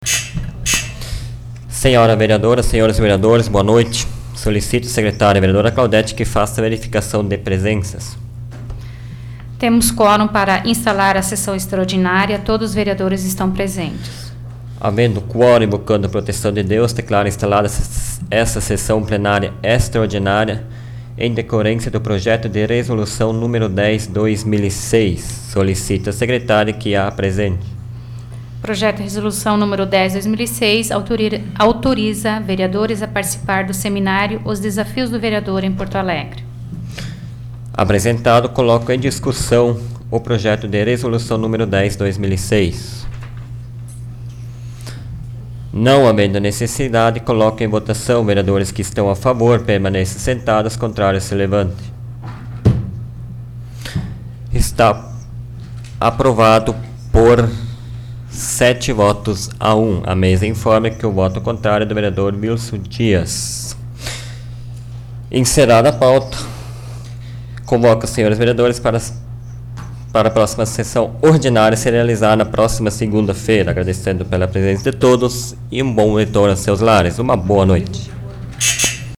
Áudio da 27ª Sessão Plenária Extraordinária da 12ª Legislatura, de 04 de dezembro de 2006